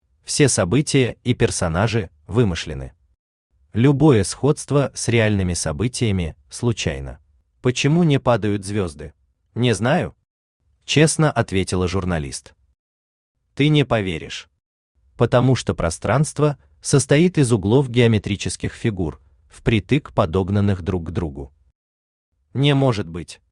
Почему не падают звезды Автор Юрий Павлович Шевченко Читает аудиокнигу Авточтец ЛитРес.